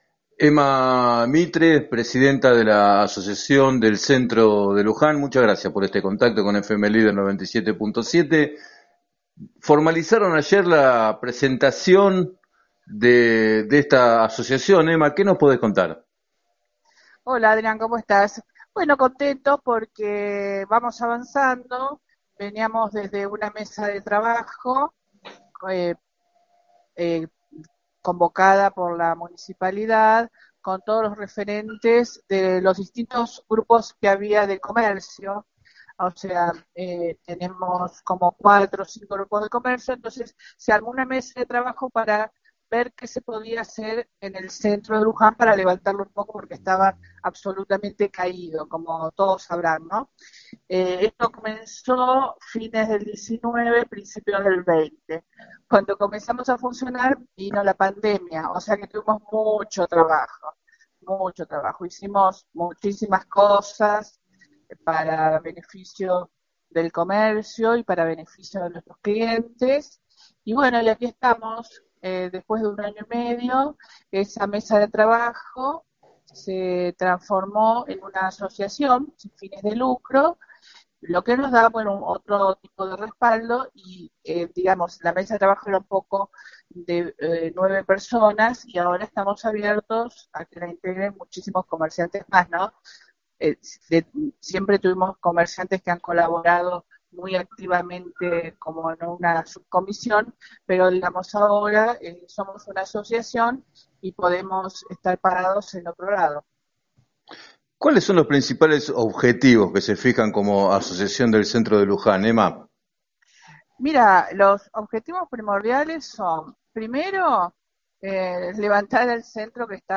declaraciones al programa “7 a 9” de FM Líder 97.7